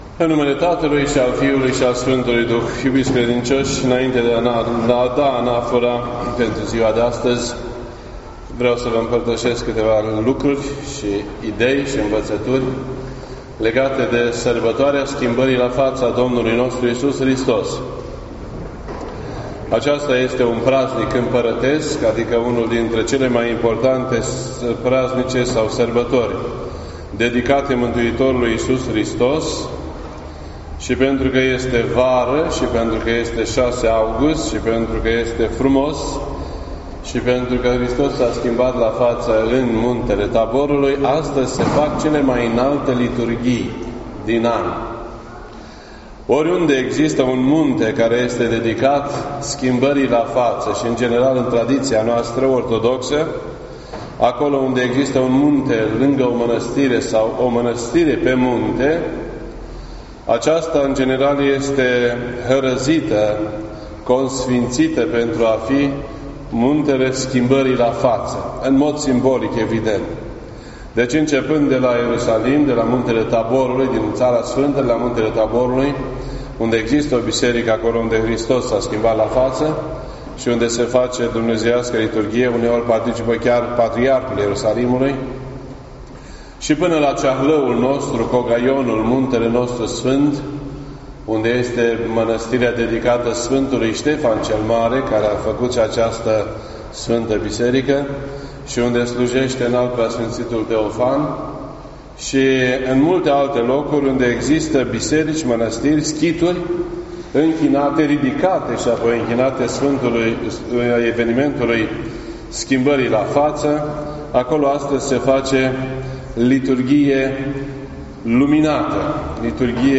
This entry was posted on Monday, August 6th, 2018 at 12:57 PM and is filed under Predici ortodoxe in format audio.